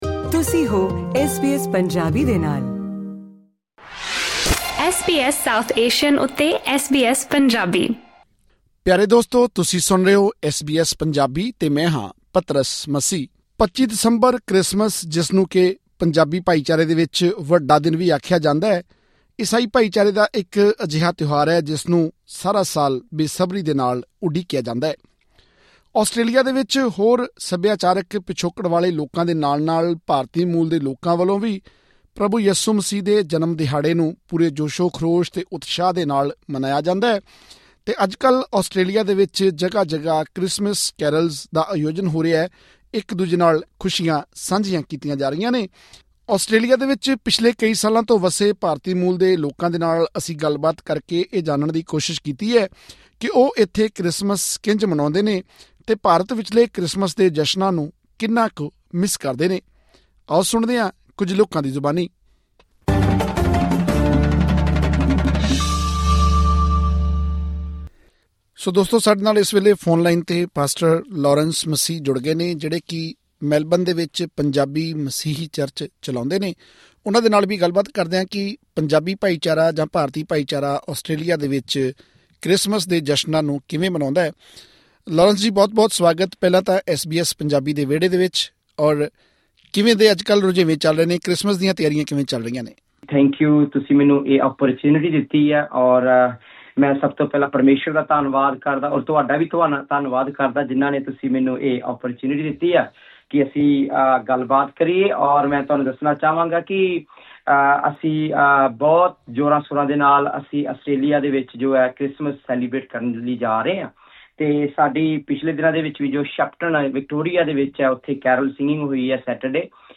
ਆਸਟ੍ਰੇਲੀਆ ਵਿੱਚ ਪਿਛਲੇ ਕਈ ਸਾਲਾਂ ਤੋਂ ਵੱਸੇ ਪੰਜਾਬੀ ਮੂਲ ਦੇ ਈਸਾਈ ਲੋਕਾਂ ਨਾਲ ਗੱਲਬਾਤ ਕਰ ਕੇ ਅਸੀਂ ਜਾਨਣ ਦੀ ਕੋਸ਼ਿਸ਼ ਕੀਤੀ ਹੈ ਕਿ ਉਹ ਇੱਥੇ ਕ੍ਰਿਸਮਸ ਕਿਸ ਤਰ੍ਹਾਂ ਮਨਾਉਂਦੇ ਹਨ ਅਤੇ ਪੰਜਾਬ ਵਿਚਲੇ ਕ੍ਰਿਸਮਸ ਦੇ ਜਸ਼ਨਾਂ ਨੂੰ ਕਿੰਨਾ ਕੁ ਯਾਦ ਕਰਦੇ ਹਨ?